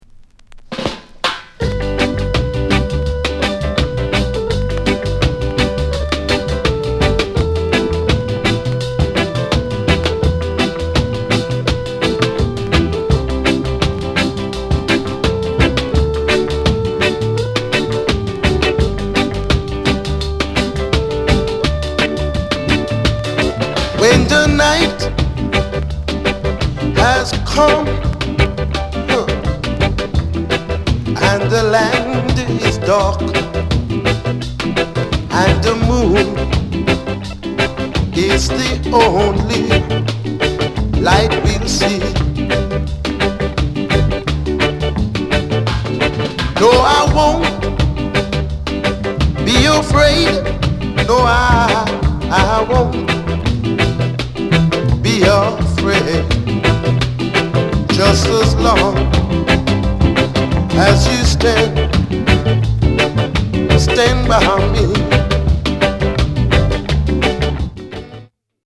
SOUND CONDITION A SIDE VG
SKINHEAD